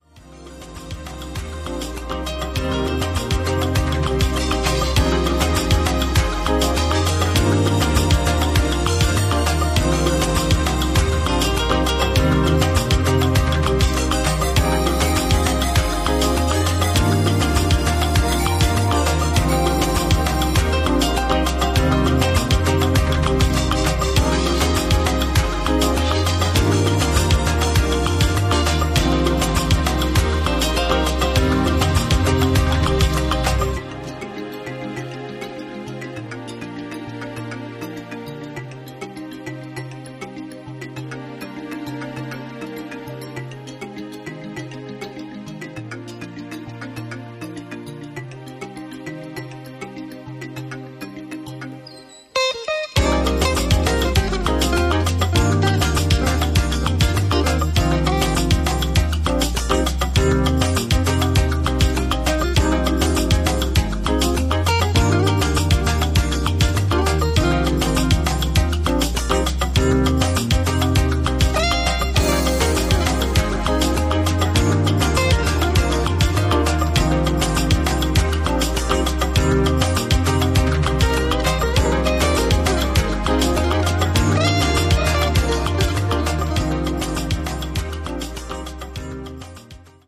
Balearic
new age motifs wash over you and carry away your woes